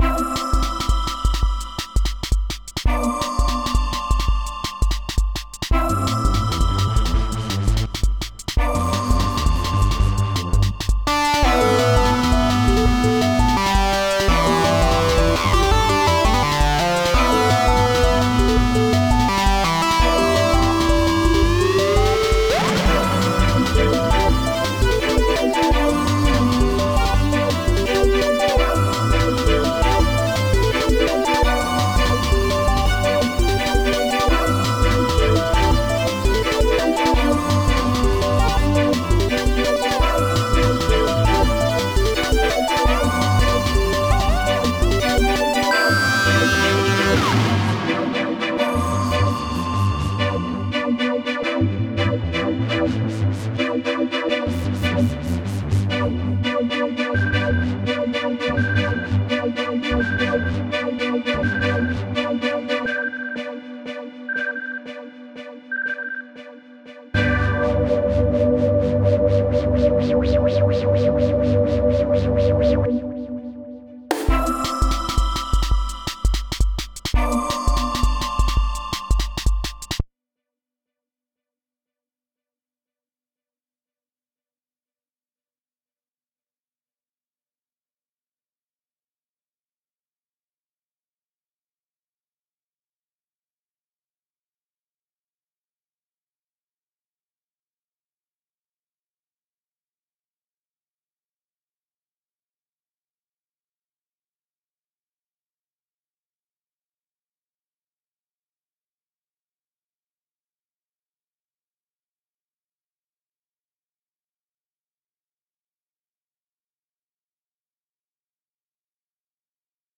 タグ: サイバー